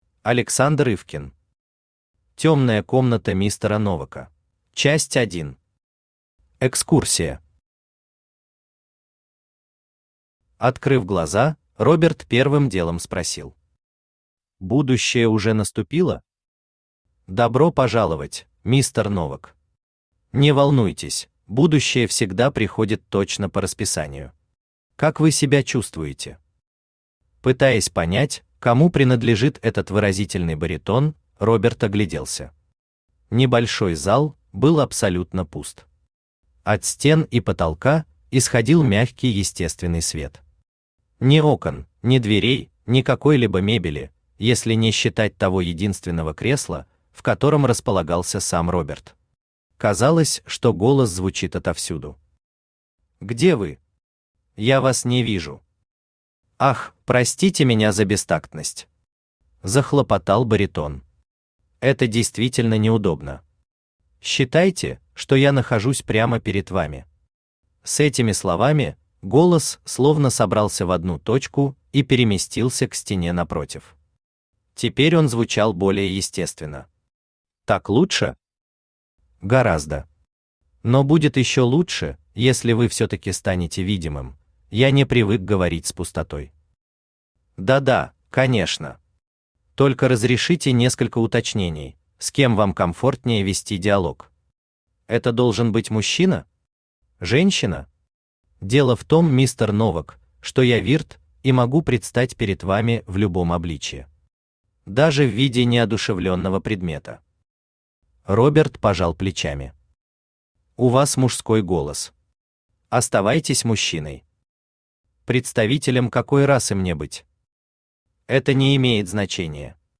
Аудиокнига Тёмная комната мистера Новака | Библиотека аудиокниг
Aудиокнига Тёмная комната мистера Новака Автор Александр Николаевич Ивкин Читает аудиокнигу Авточтец ЛитРес.